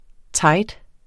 Udtale [ ˈtɑjd ]